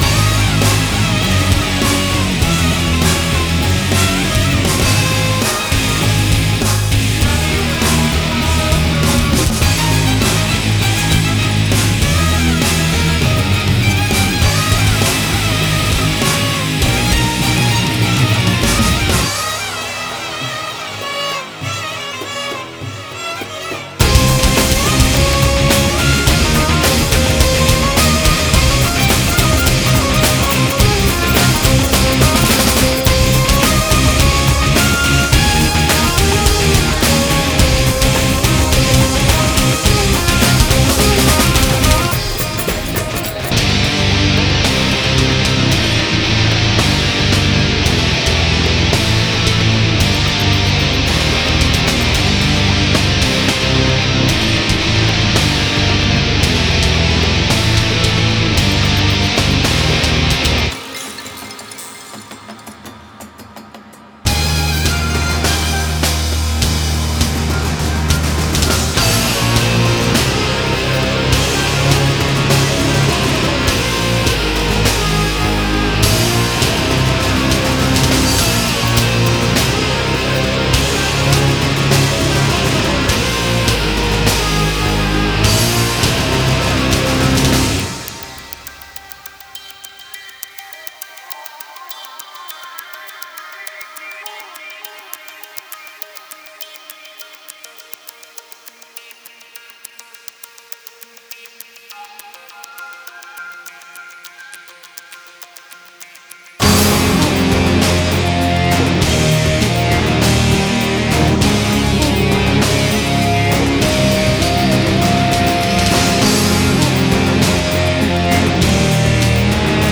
Ethnic Metal